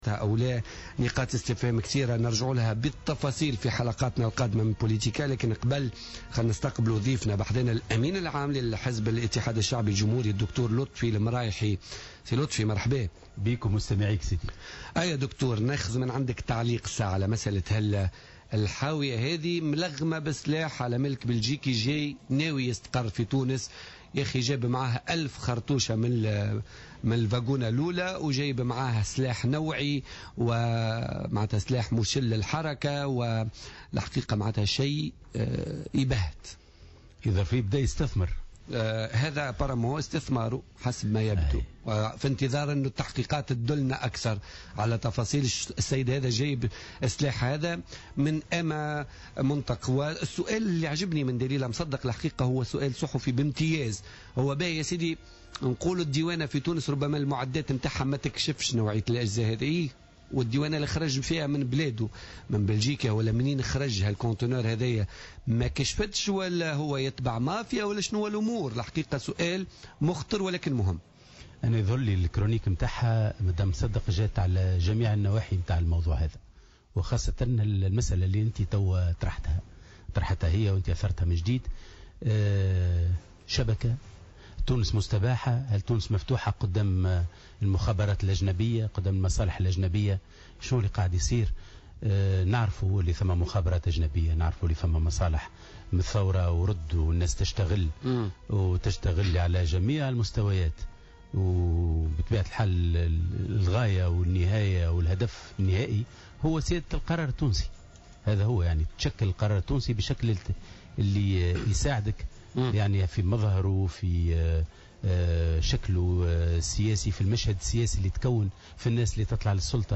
أكد لطفي المرايحي الأمين العام لحزب الاتحاد الشعبي الجمهوري ضيف بوليتيكا اليوم الأربعاء 10 فيفري 2016 أن تونس اليوم مستباحة ومفتوحة أمام المخابرات الأجنبية مضيفا ان هناك أطراف تعمل على جميع المستويات والهدف تشكيل القرار التونسي على أهوائهم ووفقا لولاءاتهم ومصالحهم الضيقة.